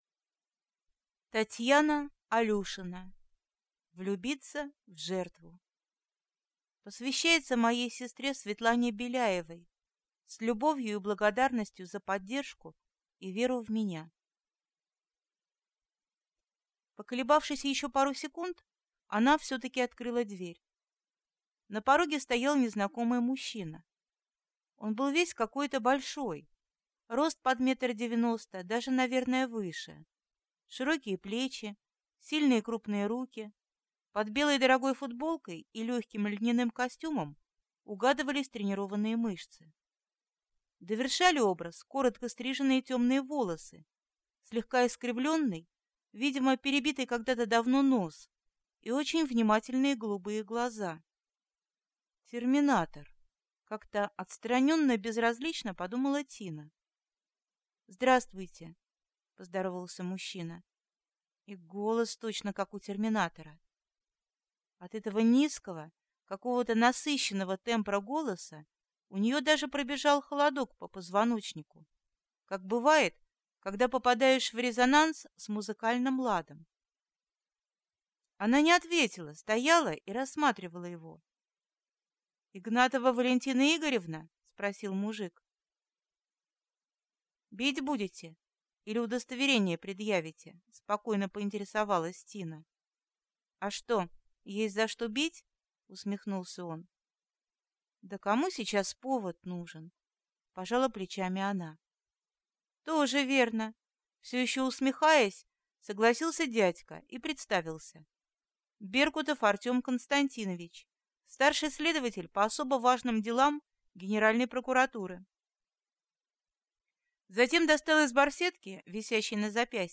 Аудиокнига Влюбиться в жертву | Библиотека аудиокниг
Прослушать и бесплатно скачать фрагмент аудиокниги